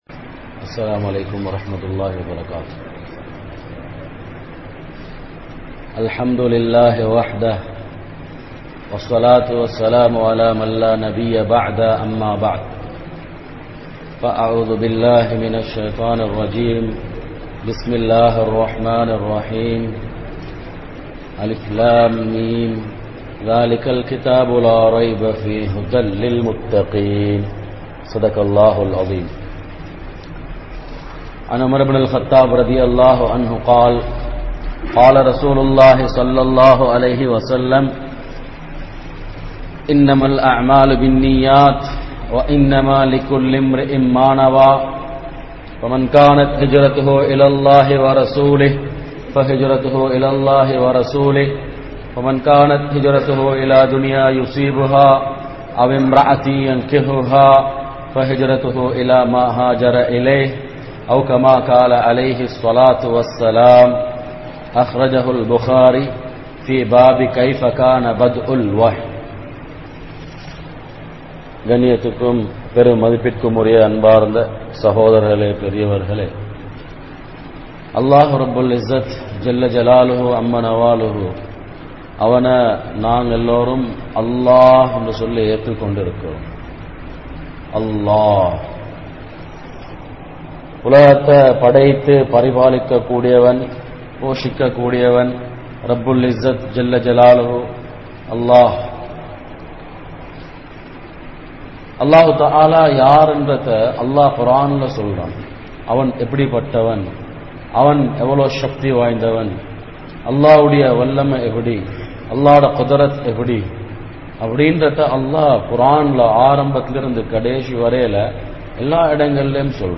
Padaithavanai Marantha Samooham (படைத்தவனை மறந்த சமூகம்) | Audio Bayans | All Ceylon Muslim Youth Community | Addalaichenai